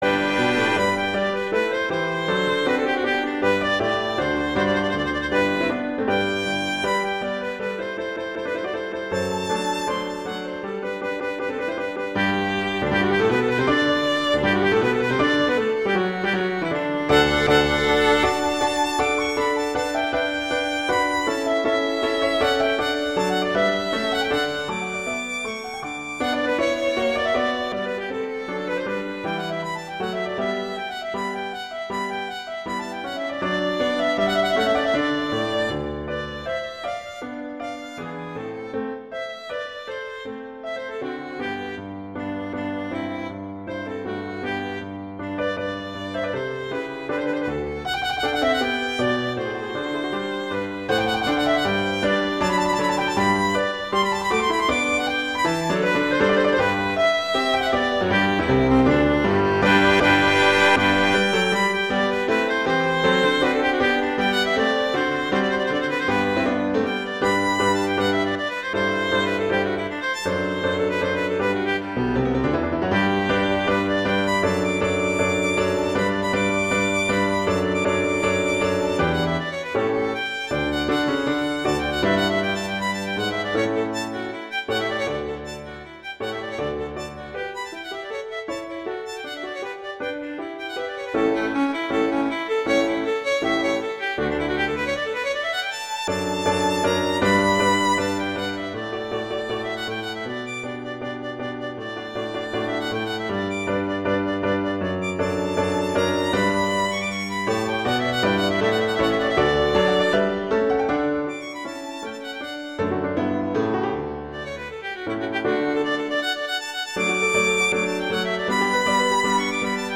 violin and piano
classical, concert
G major, C major